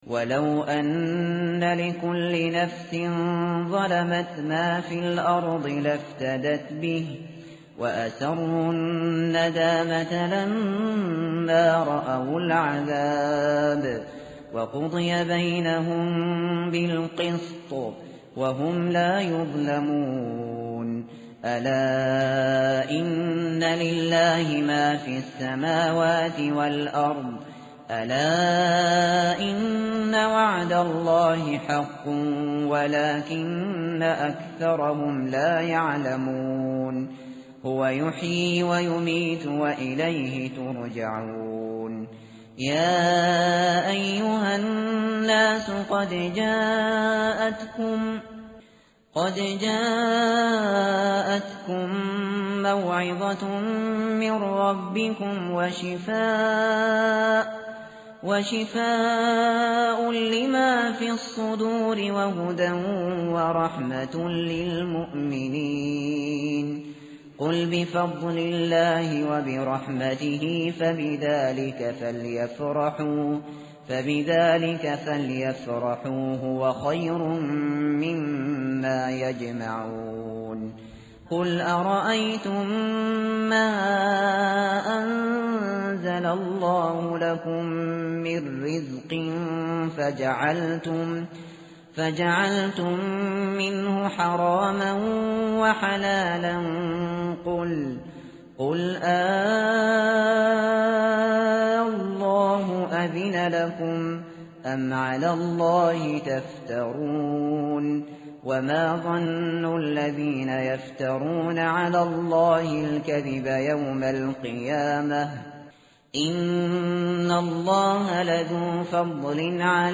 متن قرآن همراه باتلاوت قرآن و ترجمه
tartil_shateri_page_215.mp3